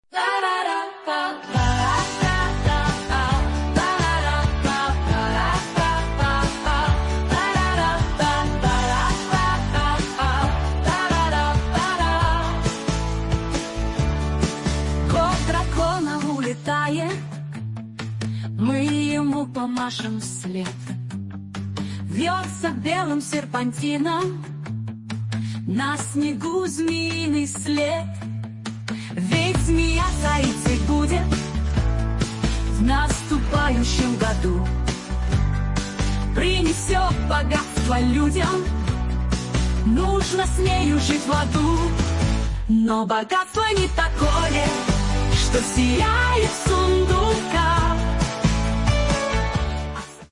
Фрагмент 1 варианта исполнения: